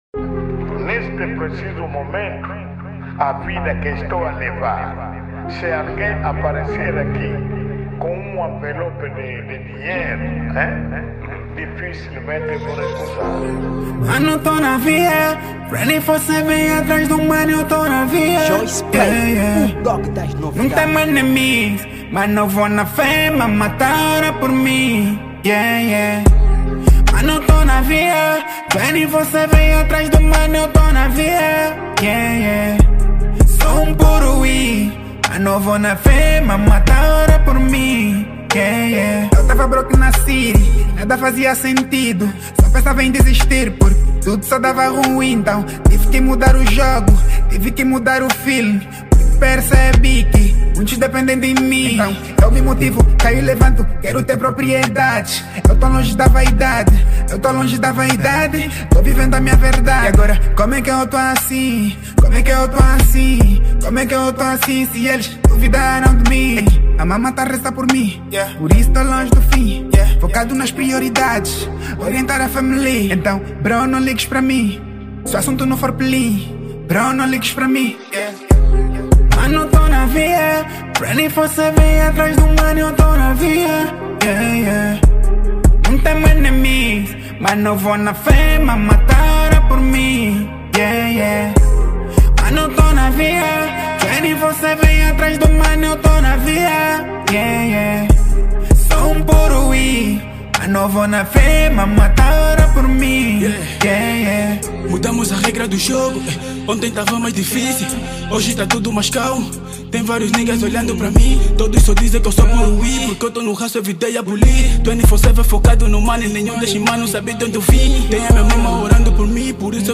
| Rap Hip-Hop